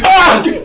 j_pain2.wav